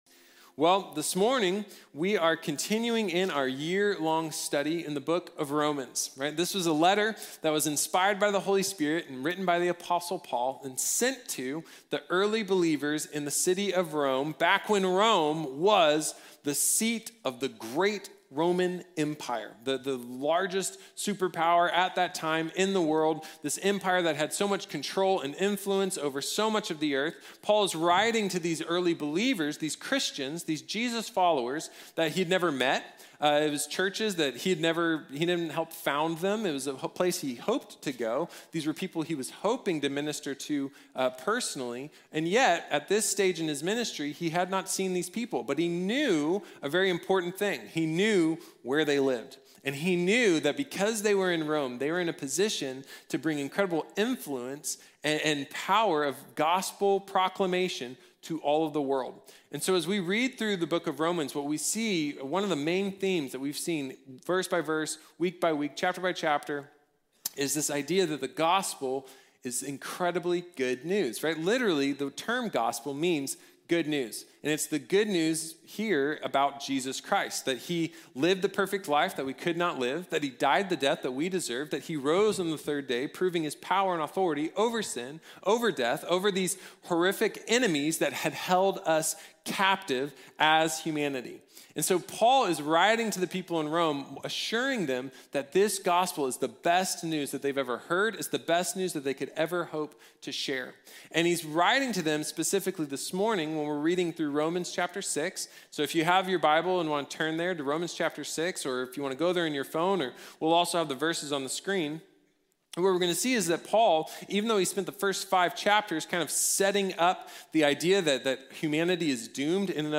Victory over Sin | Sermon | Grace Bible Church